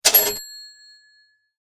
Cha Ching Sounds ringtone free download
Sound Effects